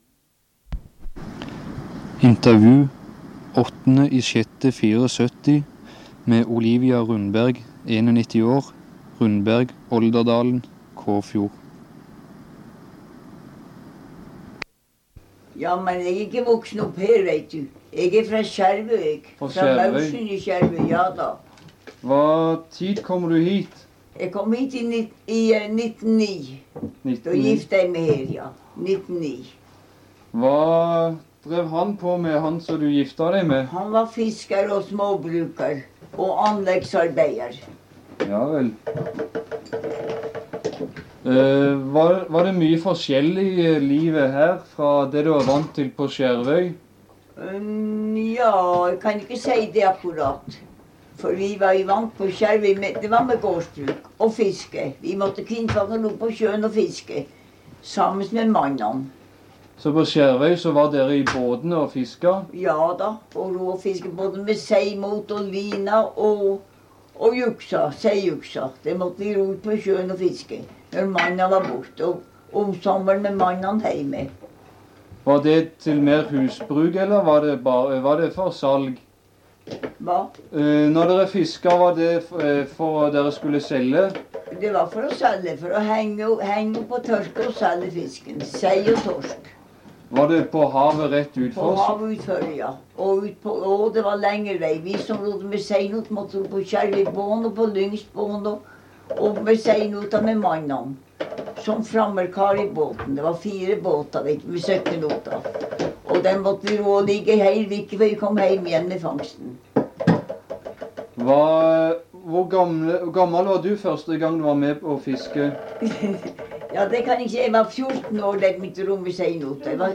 Kildetype Lydopptak